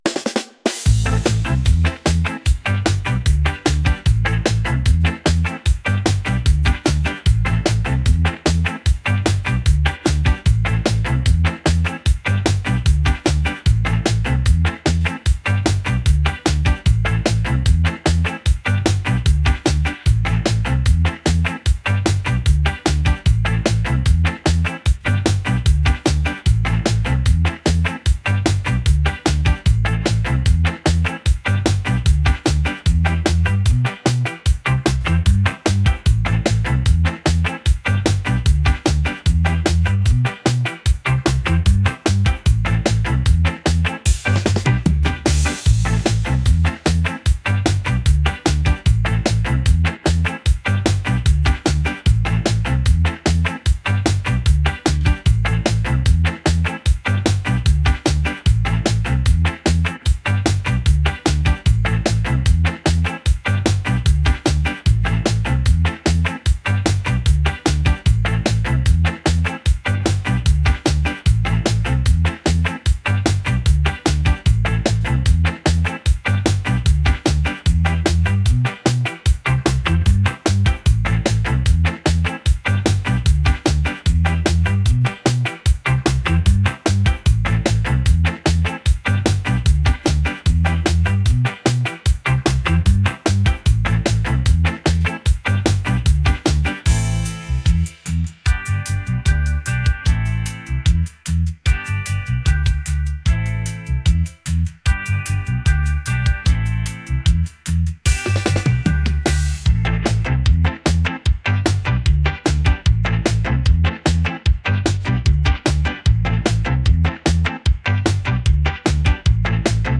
upbeat | rhythmic | reggae